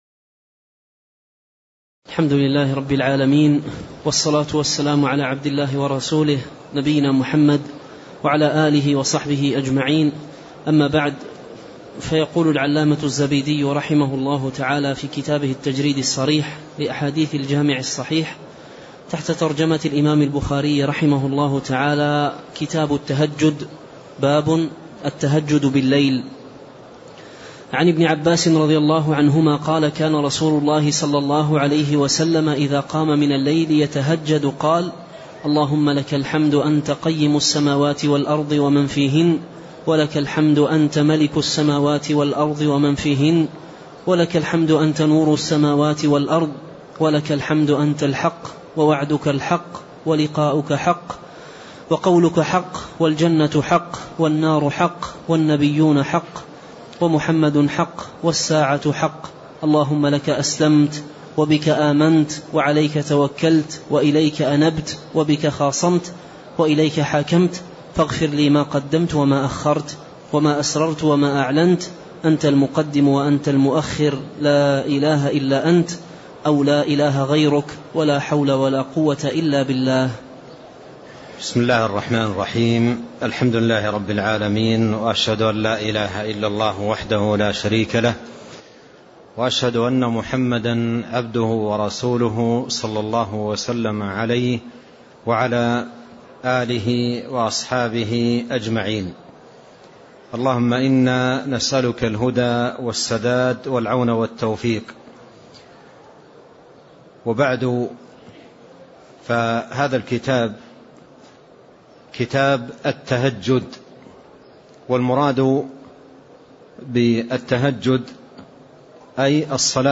تاريخ النشر ٥ جمادى الأولى ١٤٣٤ هـ المكان: المسجد النبوي الشيخ